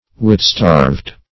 Wit-starved \Wit"-starved`\, a.